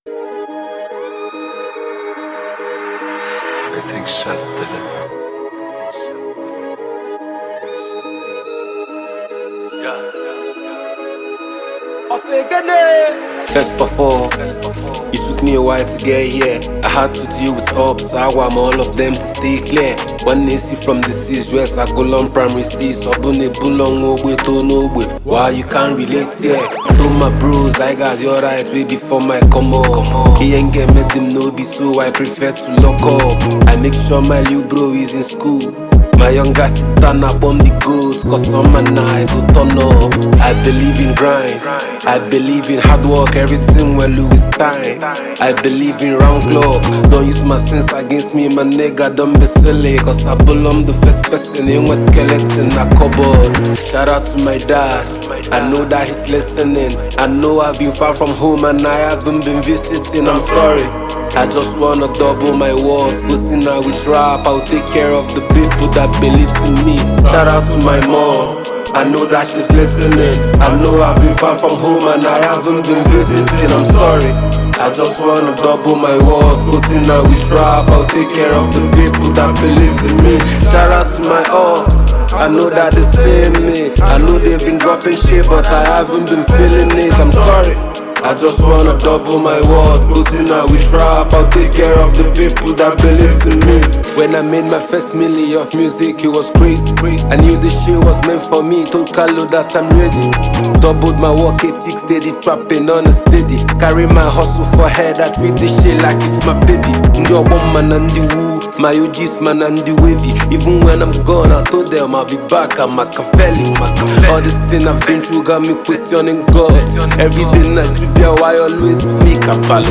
A highly talented Nigerian rap phenomenon and song composer